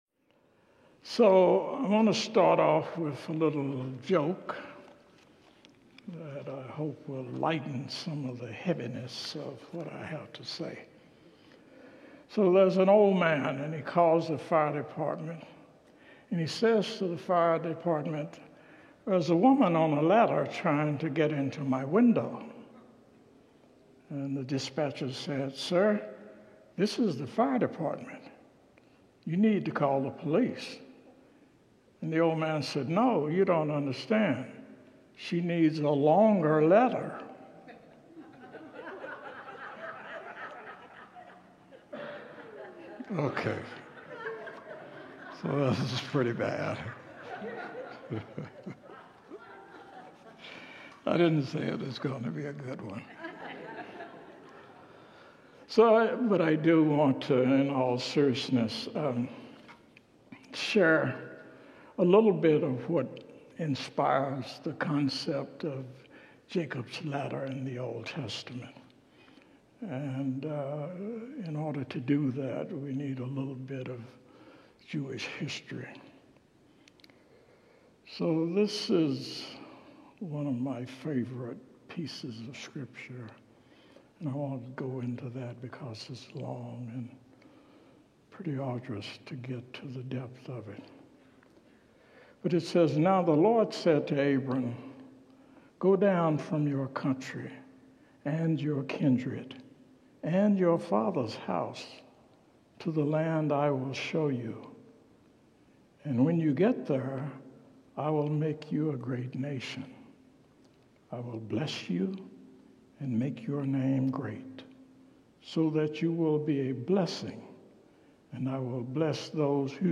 Series: Wednesday Evening Worship